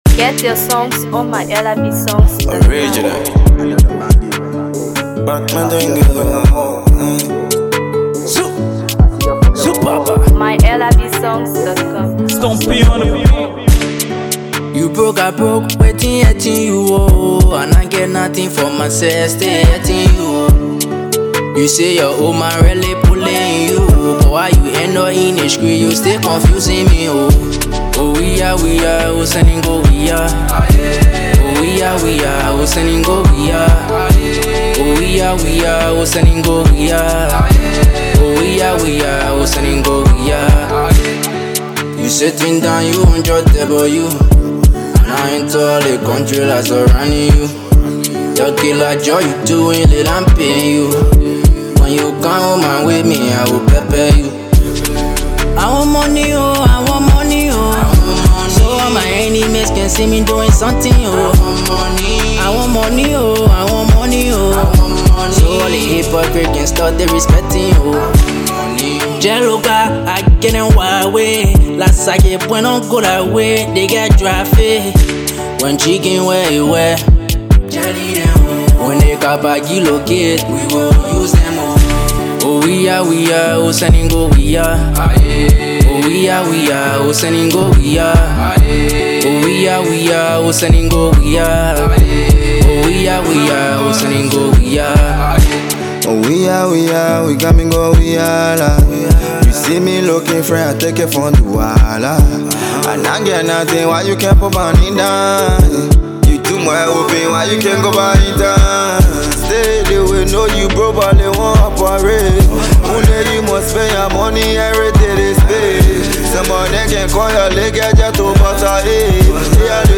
Afro PopMusic